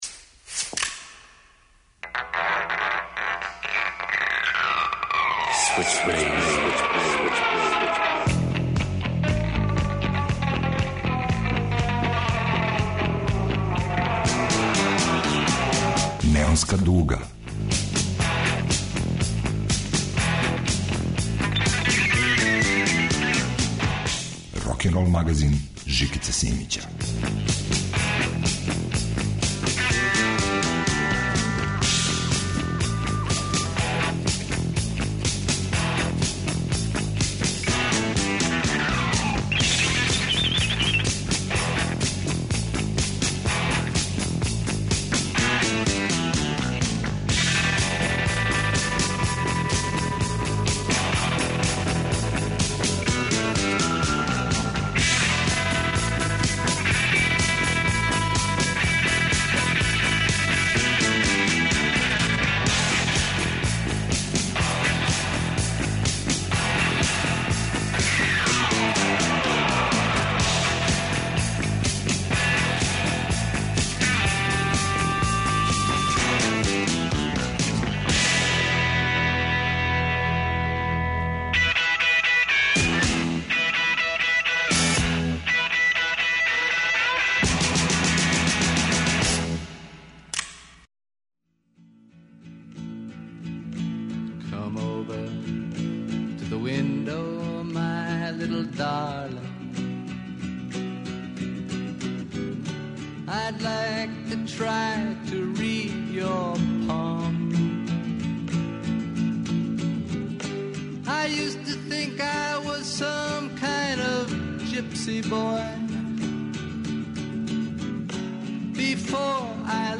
Рокенрол као музички скор за живот на дивљој страни. Вратоломни сурф кроз време и жанрове.